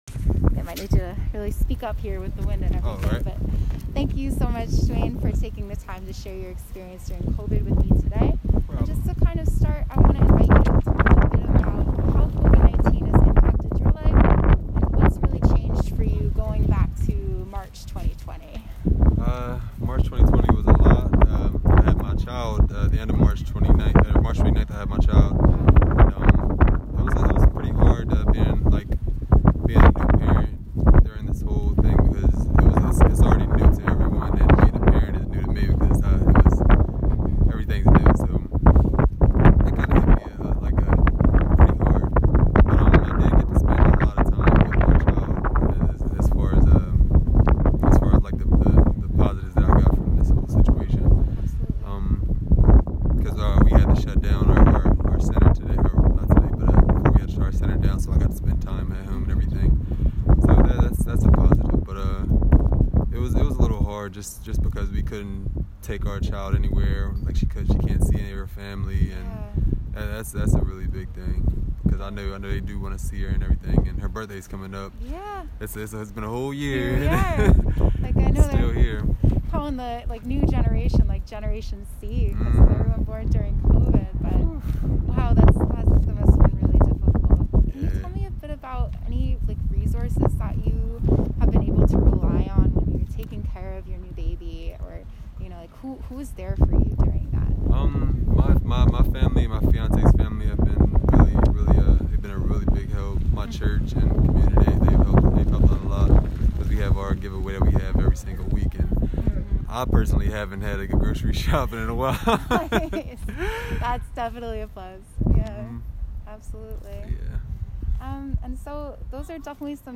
Participant 492 Community Conversations Interview